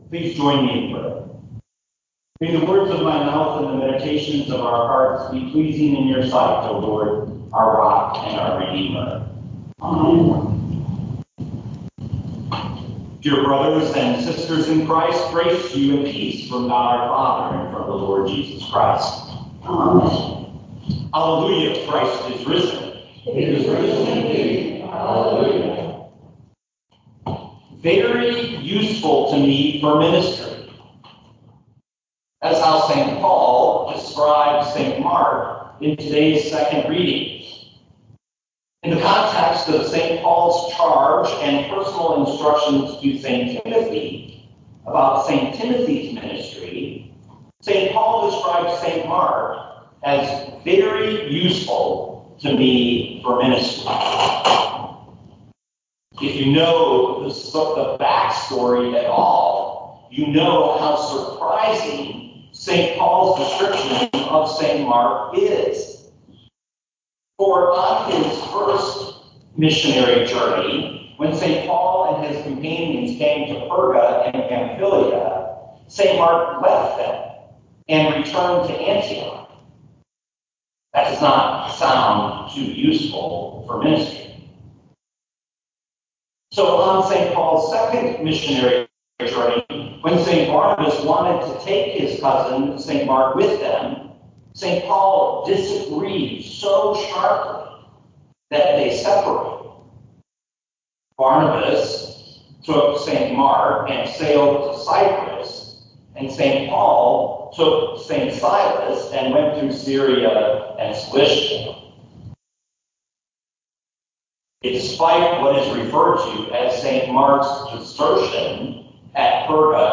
2024 2 Timothy 4:11 Listen to the sermon with the player below, or, download the audio.